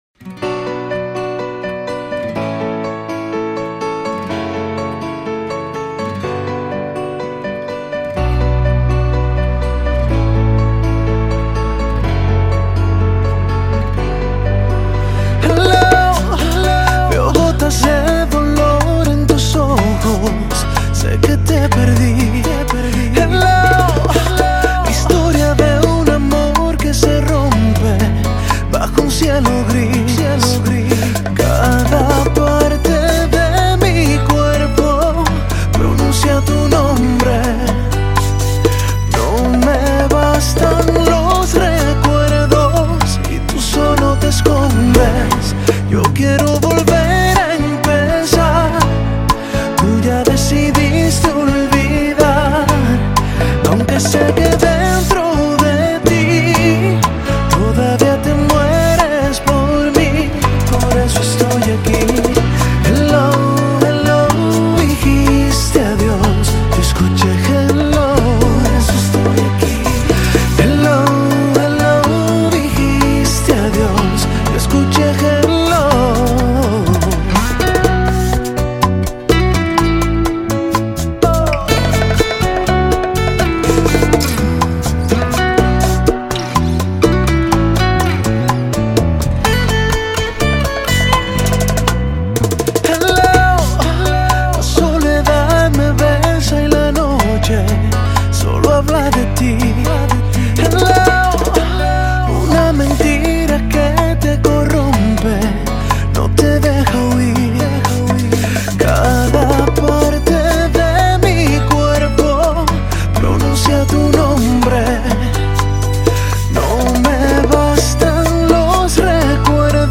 свежая бачата